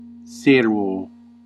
Ääntäminen
IPA: /e.paʁ.ɲe/